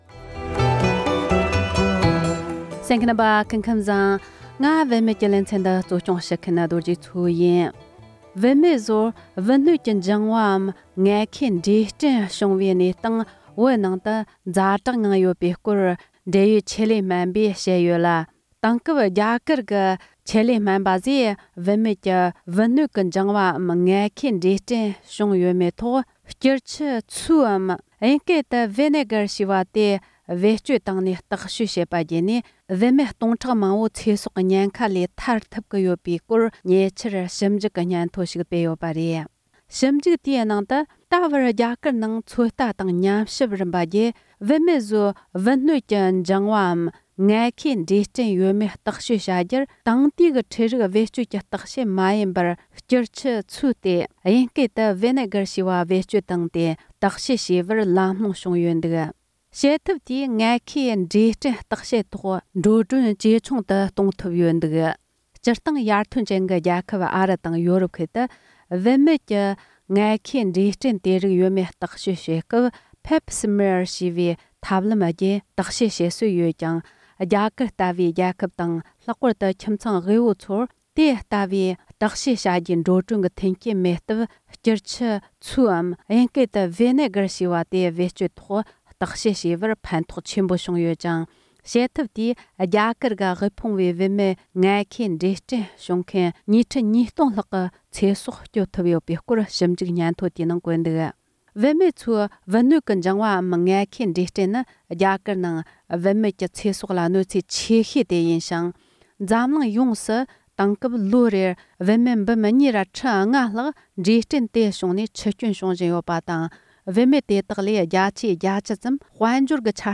ཆེད་མཁས་སྨན་པར་བཅར་འདྲི་ཞུས་པ་ཞིག